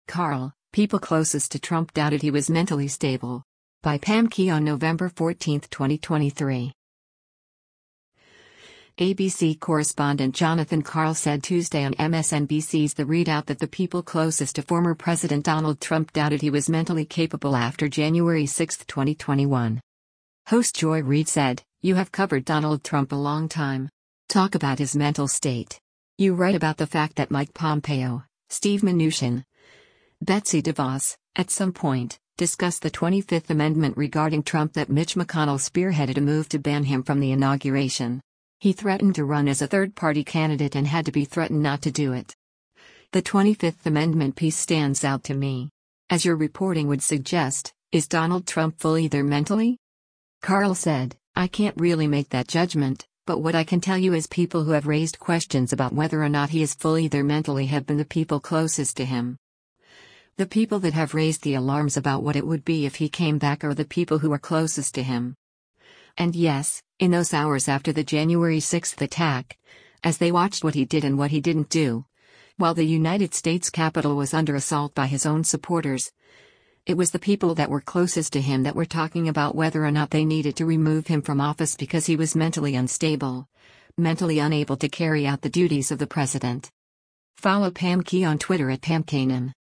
ABC correspondent Jonathan Karl said Tuesday on MSNBC’s “The ReidOut” that the people closest to former President Donald Trump doubted he was mentally capable after Jan. 6, 2021.